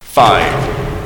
announcer_begins_5sec.mp3